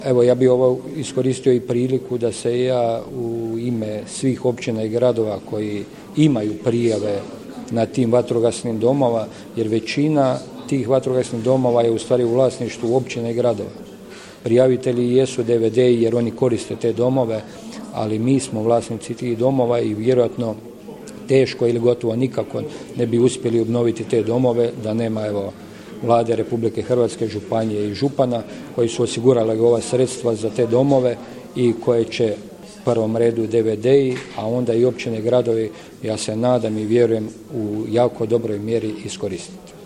U petak, 16. prosinca 2022. godine, u prostoru DVD-a Desna Martinska Ves predstavljen je Javni poziv za dodjelu bespovratnih sredstava za obnovu objekata koje koriste javne vatrogasne postrojbe i dobrovoljna vatrogasna društva na potresom pogođenom području.
Načelnik Općine Martinska Ves Stjepan Ivoš